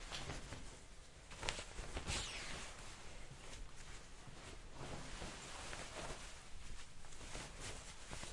穿上外套
描述：一个人穿上夹克外出。
Tag: 衣服 服装 运动 夹克 沙沙声 材料 人员 面料 OWI